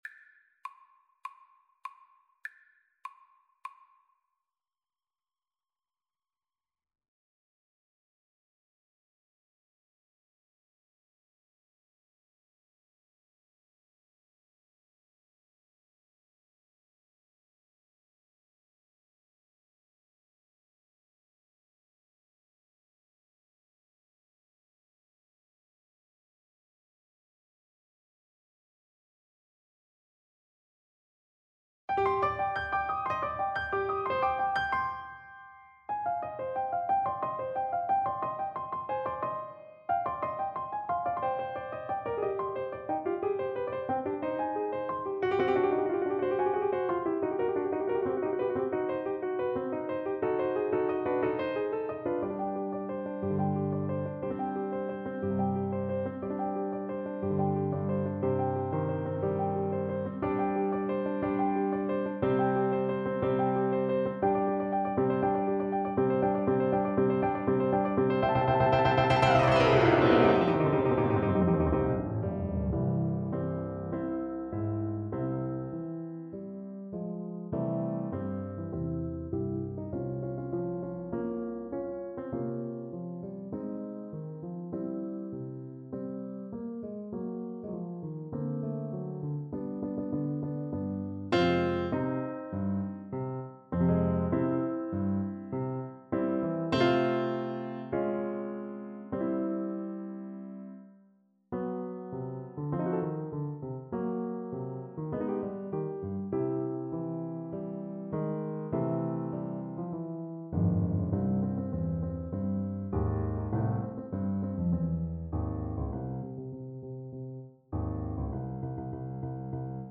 French Horn
4/4 (View more 4/4 Music)
Allegro moderato (View more music marked Allegro)
Classical (View more Classical French Horn Music)